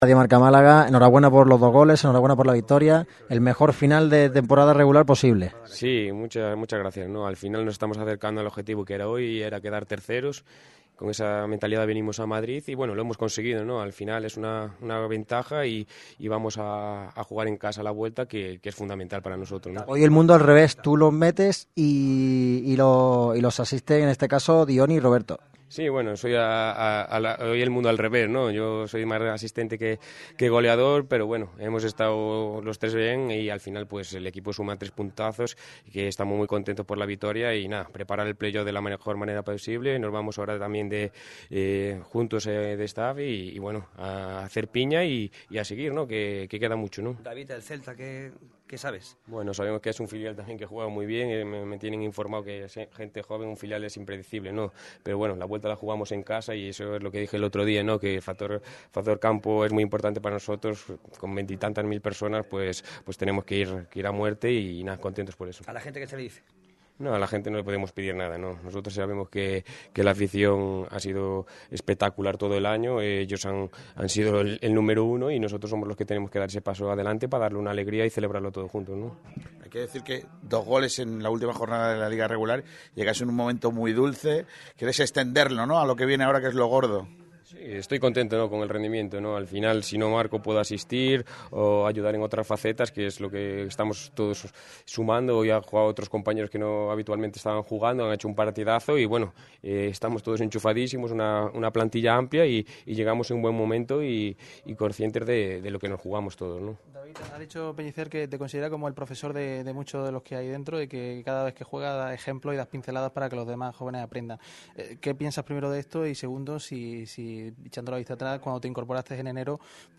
Estas fueron las palabras del ‘7’ en la zona mixta del Alfredo Di Stéfano